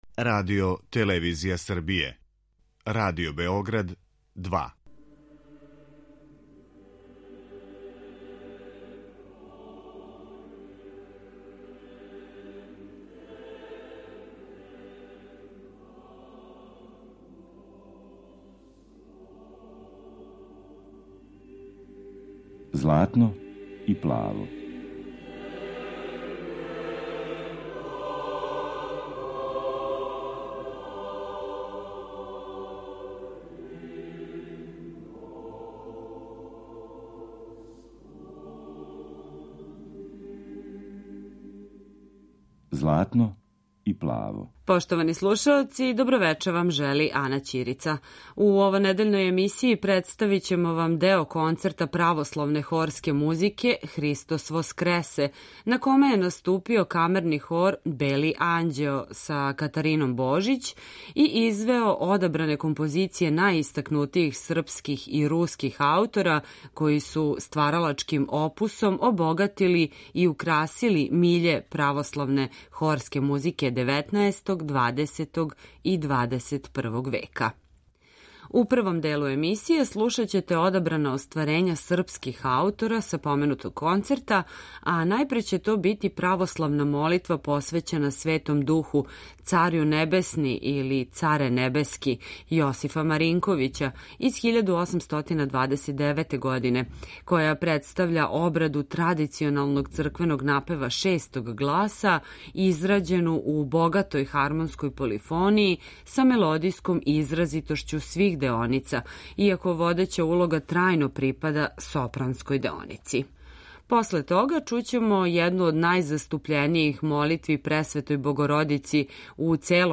Концерт „Христос Воскресе”
Камерни хор „Бели анђео”